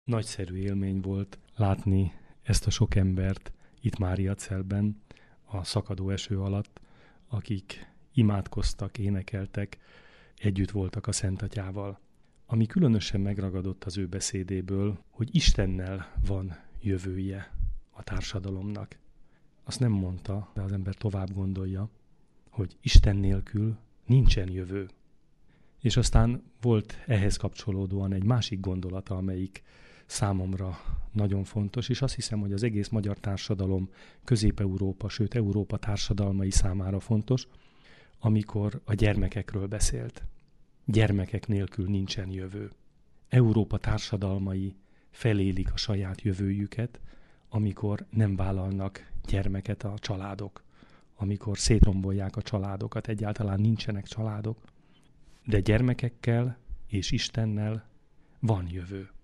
A pápai szentmise után Ternyák Csaba egri érsek